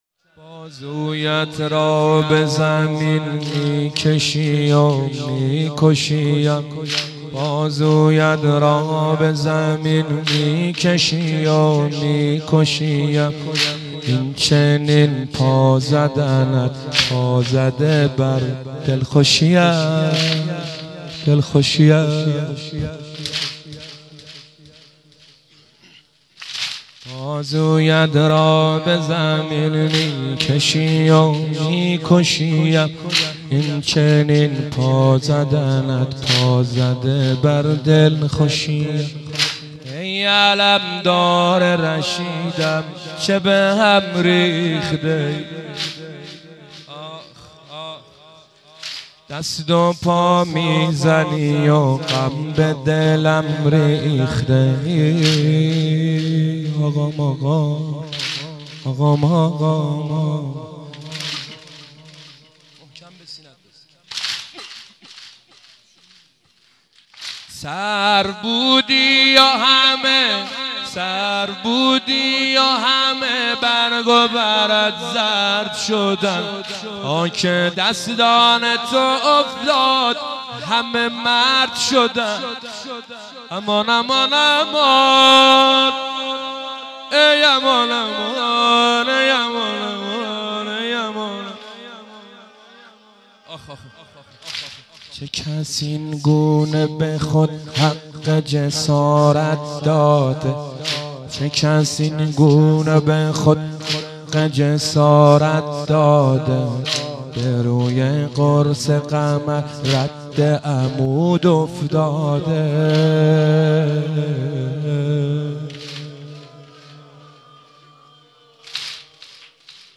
شب هفتم رمضان95، حاج محمدرضا طاهری